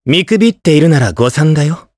Evan-Vox_Skill2_jp.wav